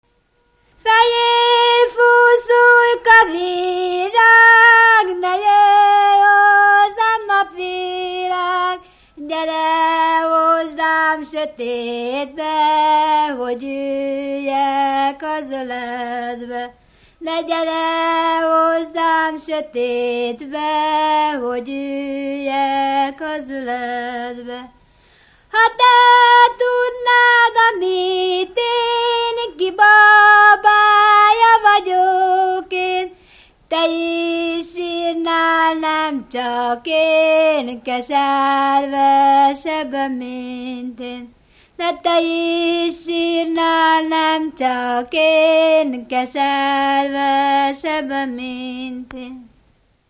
Erdély - Kolozs vm. - Méra
Stílus: 2. Ereszkedő dúr dallamok
Szótagszám: 7.7.7.7
Kadencia: #7 (5) 3 1